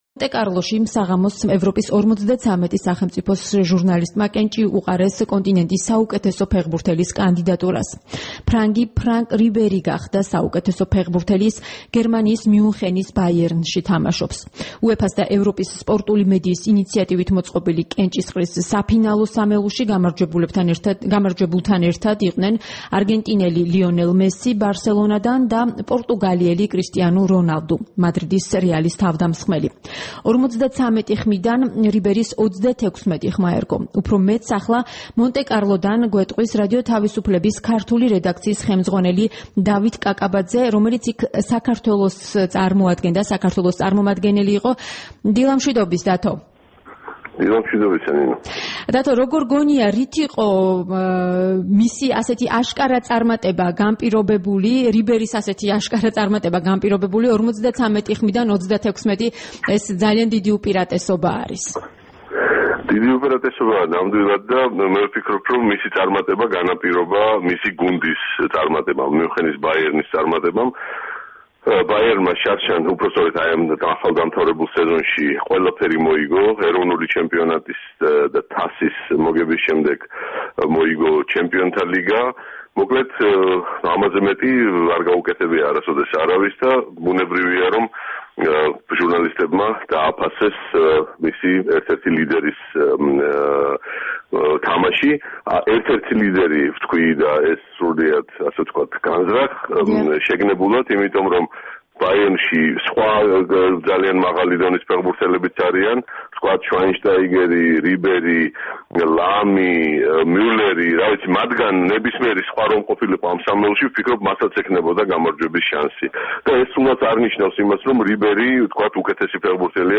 რეპორტაჟი მონტე კარლოდან